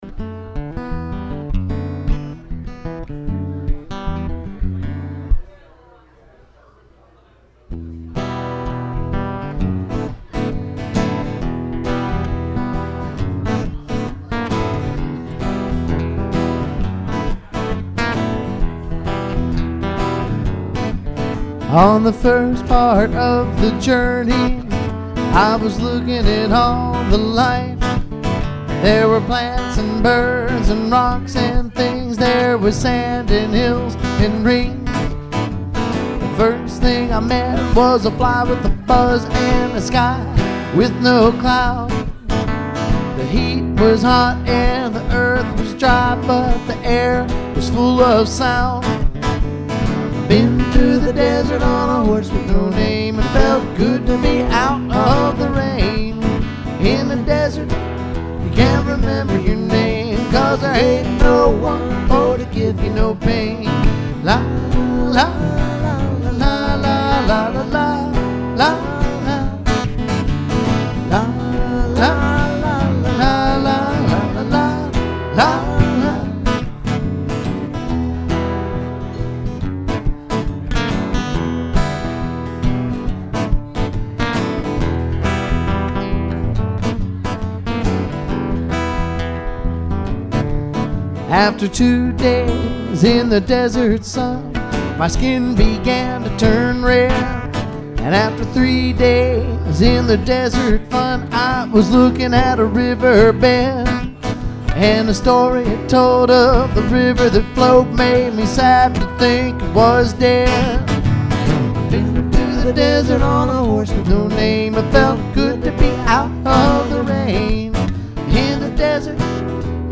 Live Tunes